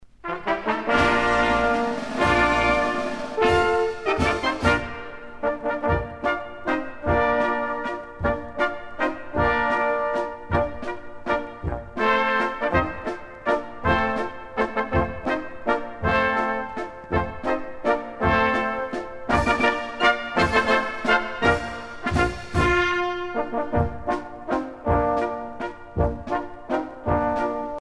danse : valse